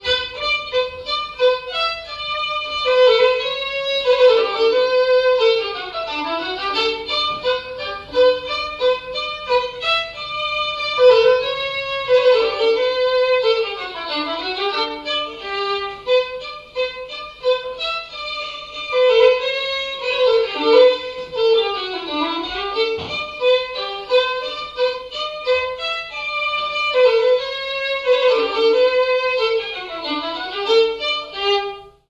danse : scottish
répertoire de bals et de noces
Pièce musicale inédite